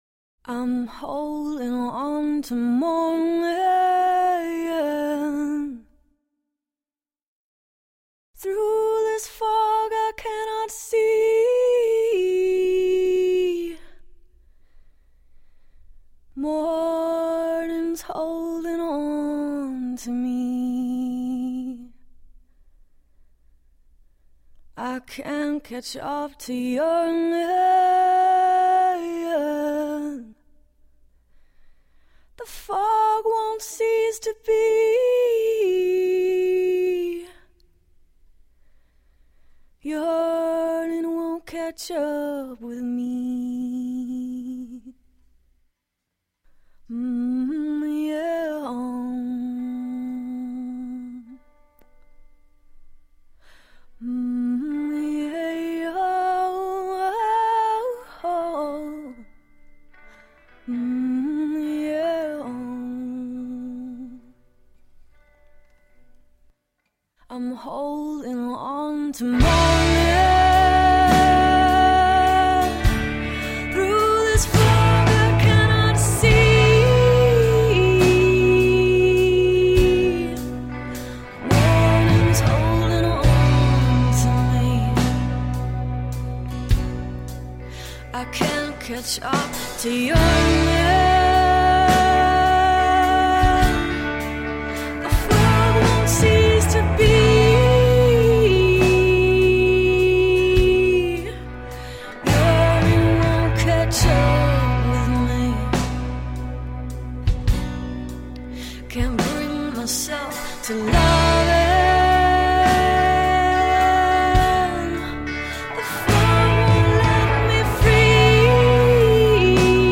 Indie sound, southern soul.
Tagged as: Alt Rock, Folk-Rock